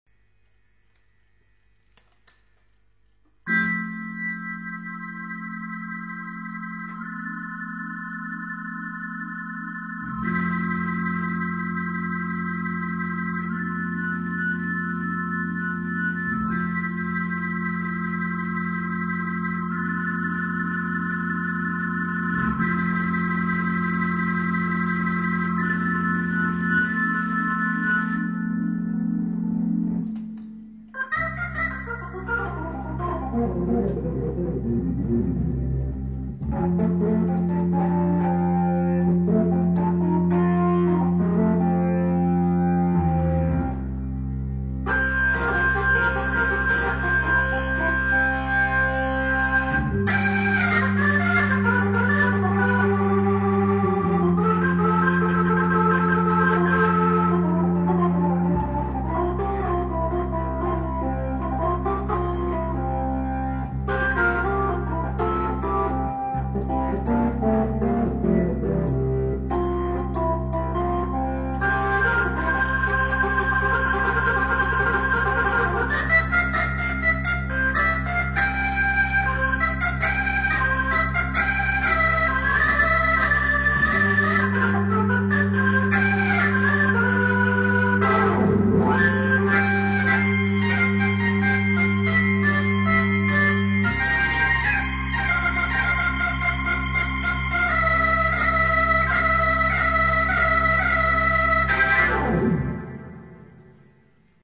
Click here for a demo MP3, featuring the VIP-255 through a GRS Leslie.  Settings: First part upper 16', 8', 2-2/3' lower 8', 4'  Second part upper  16', 8'. 5-1/3' (all flute voices, I presume)